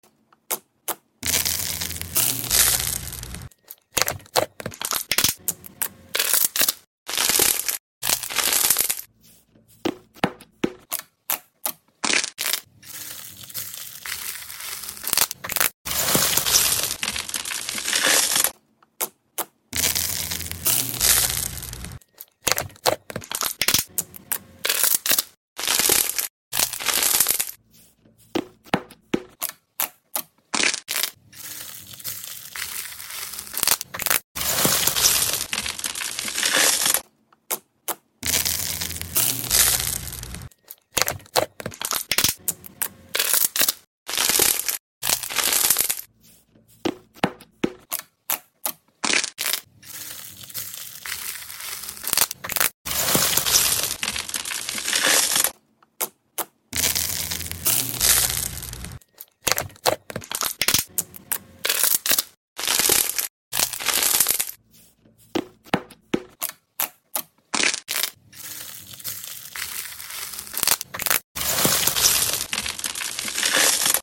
genre is bossa nova/pop/jazz